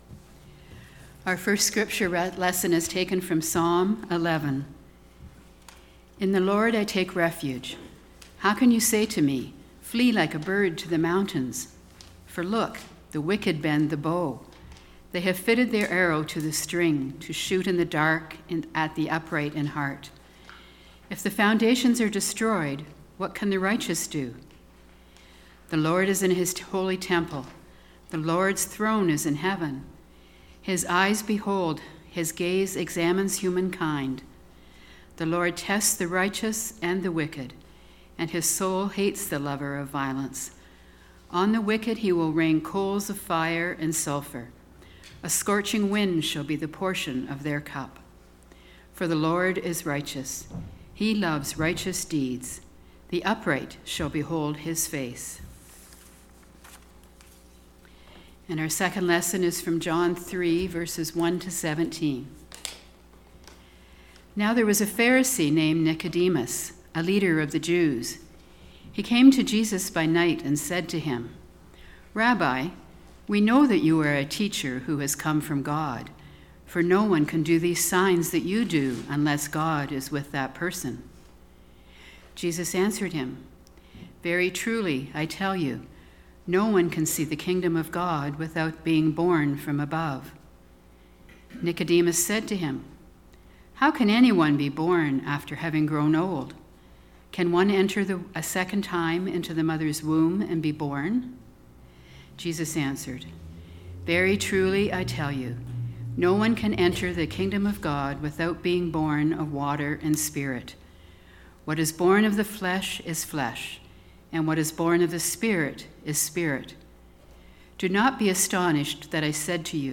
Web Service for March 1, 2nd Sunday in Lent
We hear God’s Word:  Psalm 11 and John 3: 1-17